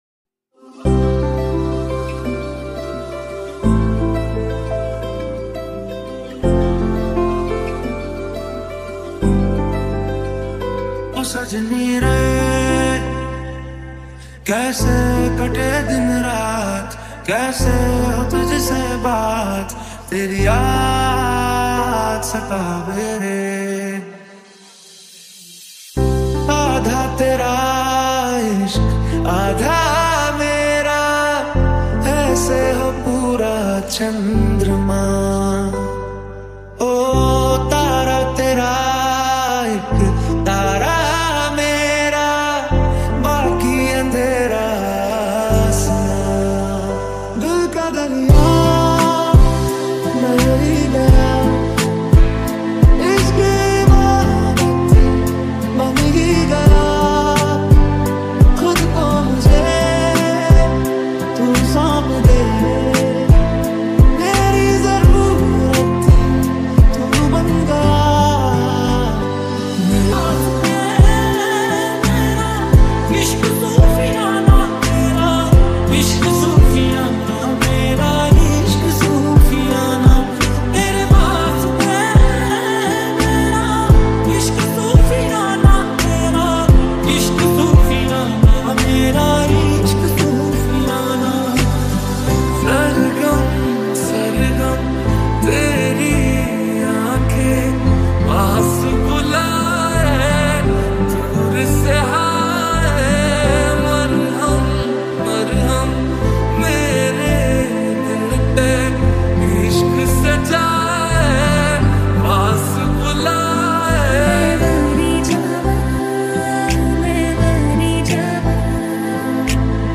slowed and reverb mashup song